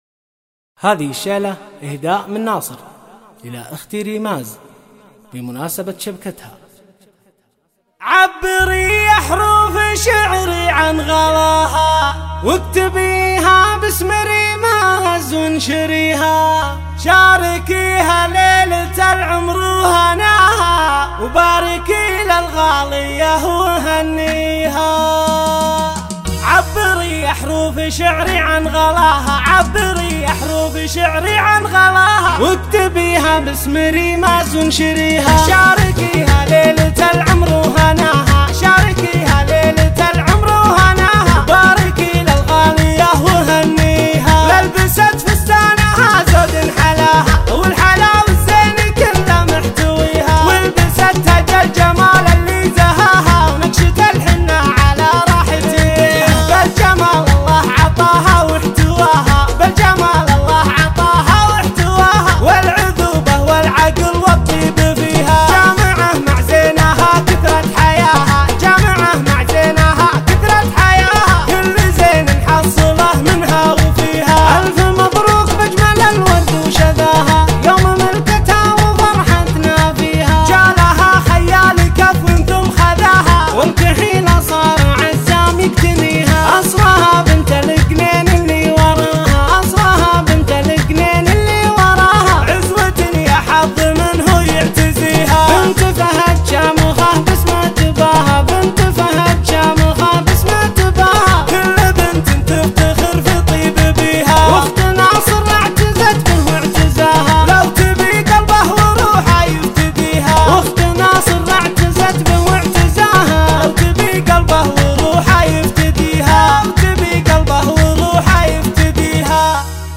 شغل الشيله على اللحن الحماسي